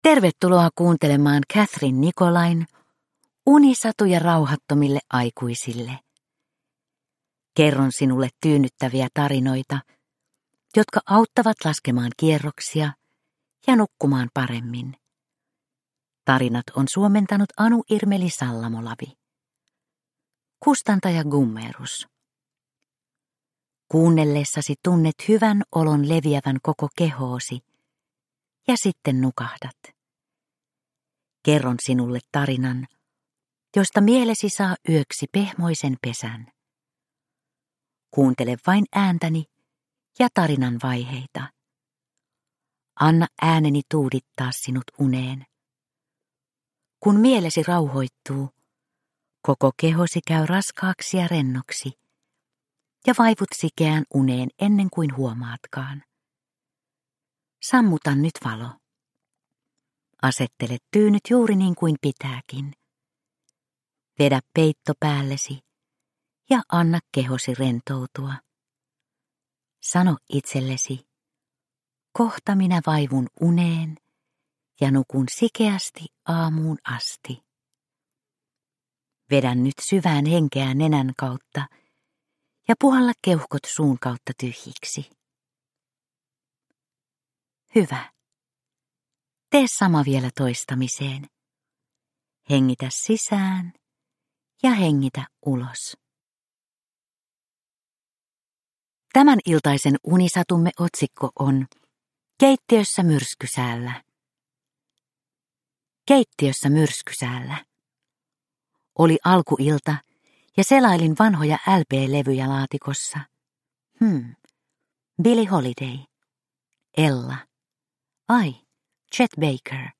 Unisatuja rauhattomille aikuisille 44 - Keittiössä myrskysäällä – Ljudbok – Laddas ner